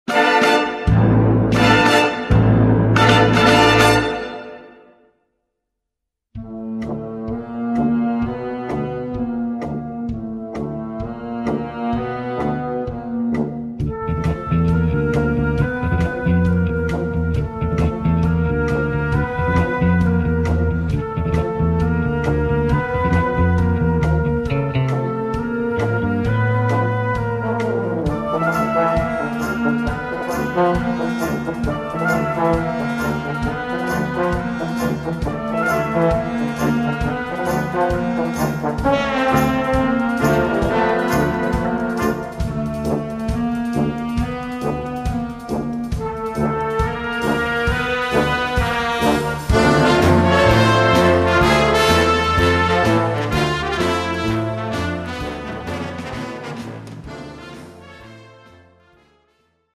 Gattung: Filmmusik
A4 Besetzung: Blasorchester Zu hören auf